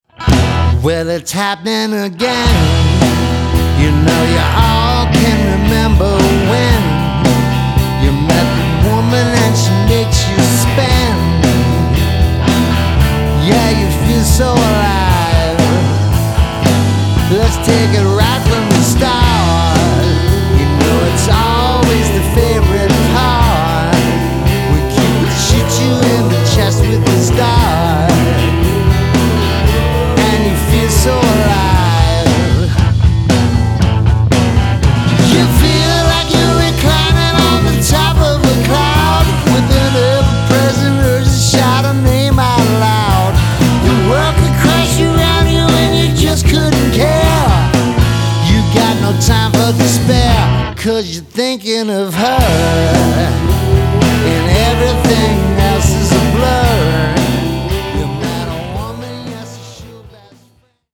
guitar, drums, vocals
bass